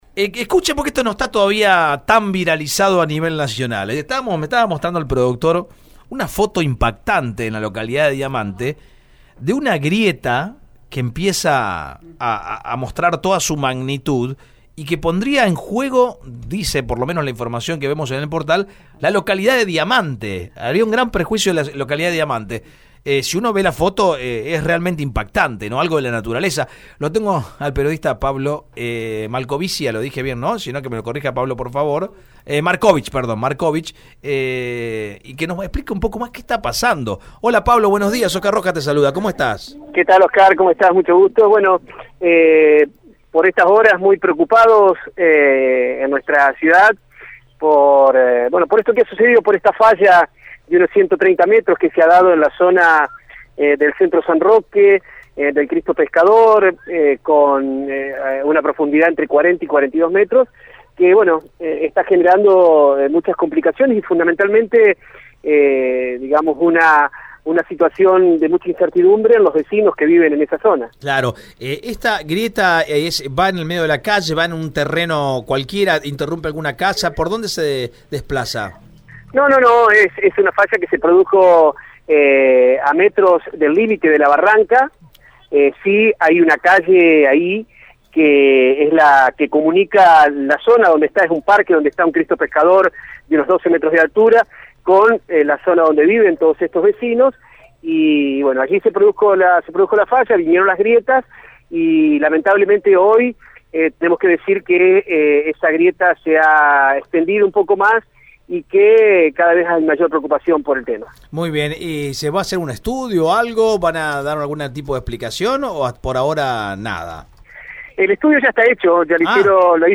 periodista de Diamante, contó por Radio EME la situación.